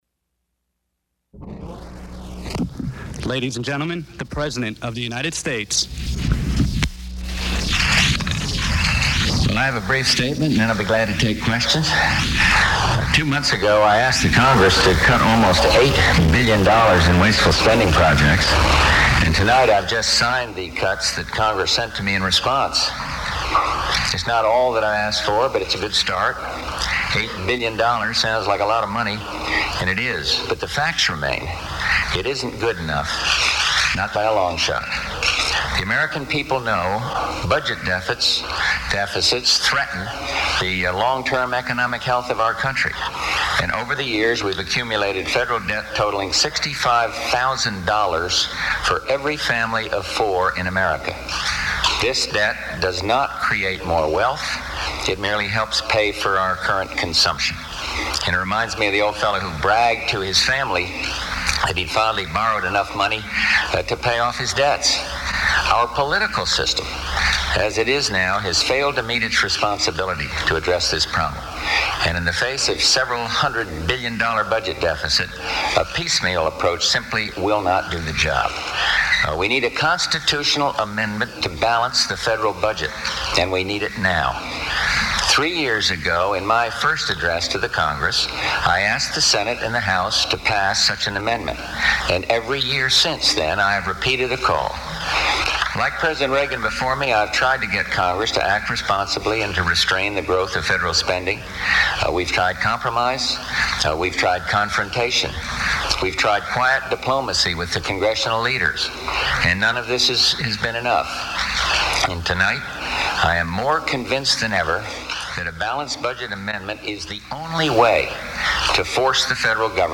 U.S. President George Bush appeals for a balanced budget at a press conference
Second prime-time press conference of his four year administration begins with an appeal for a balanced budget amendment. Much of the Q-and-A session deals with the possible presidential candidacy of H. Ross Perot.
Broadcast on CNN, June 4, 1992.